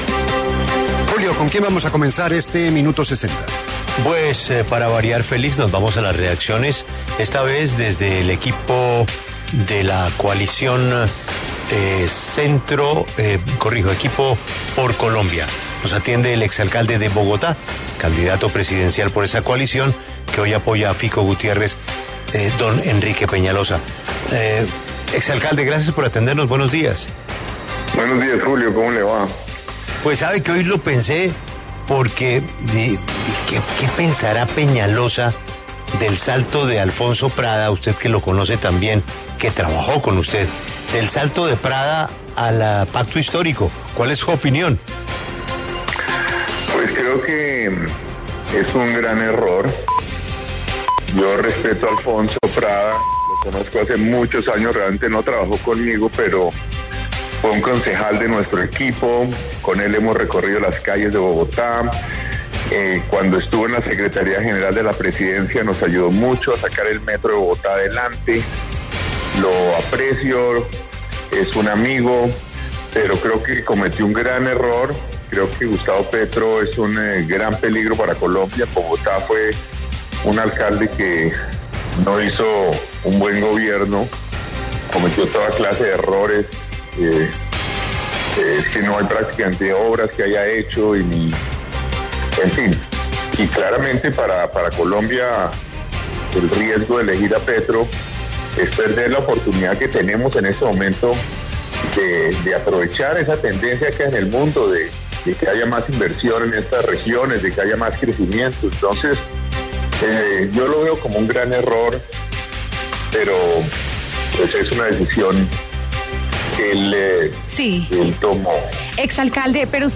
En diálogo con La W, el exalcalde Enrique Peñalosa hizo duras críticas a Gustavo Petro y lo que representaría para el país un eventual gobierno suyo.